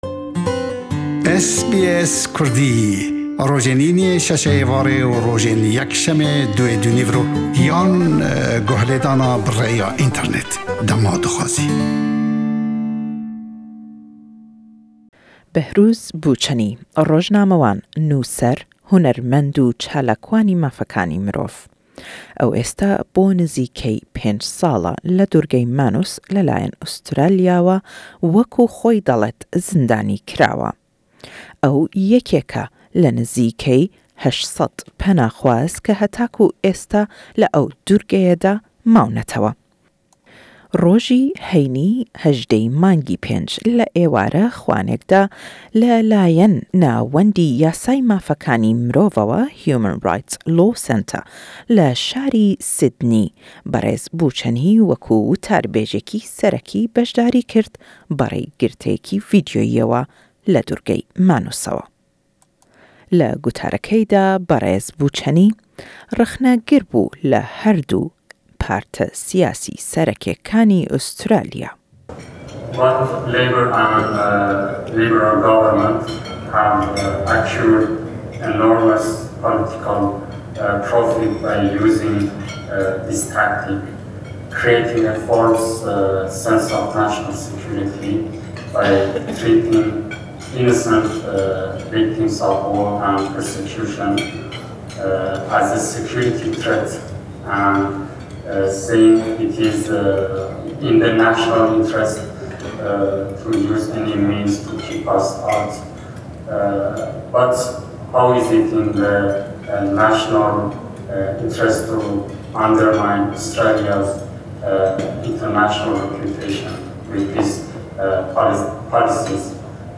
Le witarêkî da le boney Nawendî Yasay Mafekanî Mirov le şarî Sydney, Rojnamewanî Kurd Behrouz Boochanî ke le Durgey Manus e wek penaxwaz bo nizîkey pênc salle. Le witarekey day rîxnegir bû le herdû parte sîyasî serkî Australya- û le lêdwanêkî taybetman le gell ewẍ raygeyand ke xellkî Australya dirrinde nîn bellam firîw dirawin le layen hukûmete we.